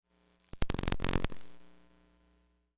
Звуки электромагнитного поля
Звук краткого взаимодействия с электромагнитным полем